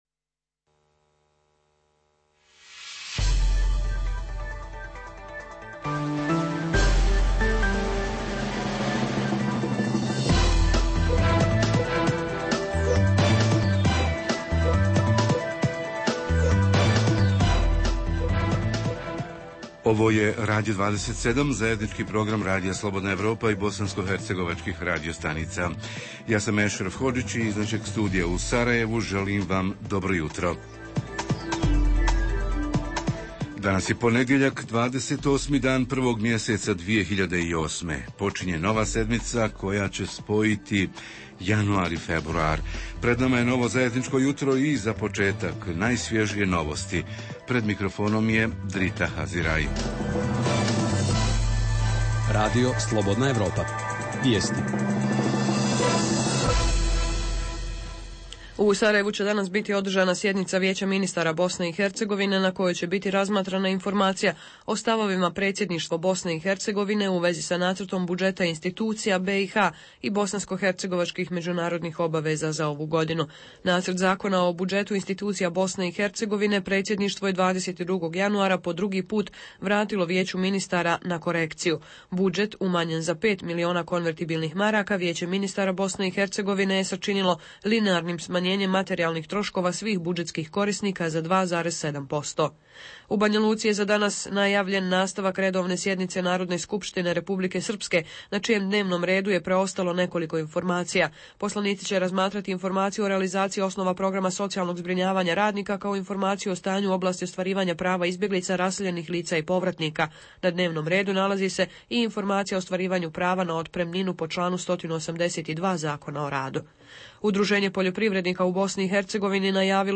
Jutarnji program za BiH koji se emituje uživo. Ovog jutra: najaktuelnije minulog vikenda u BiH i regionu, a reporteri iz cijele BiH javljaju o najaktuelnijim događajima u njihovim sredinama.
Redovni sadržaji jutarnjeg programa za BiH su i vijesti i muzika.